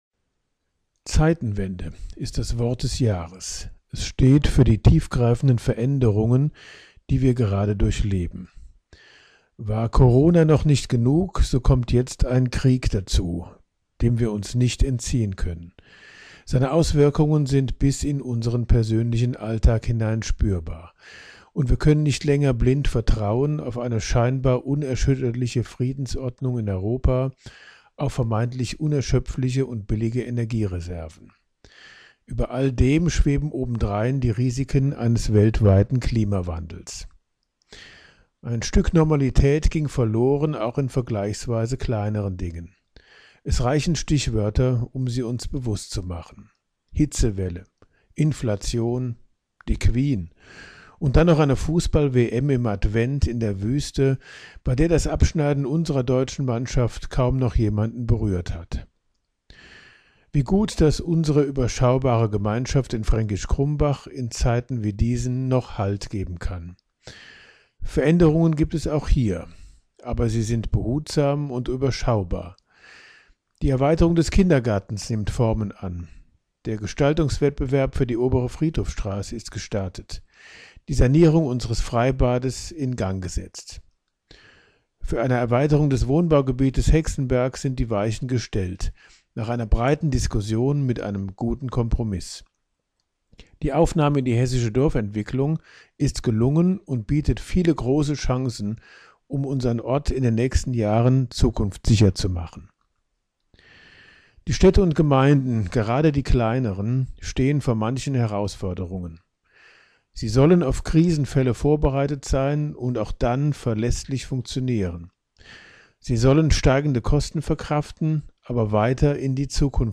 Weihnachtsgruß des Bürgermeisters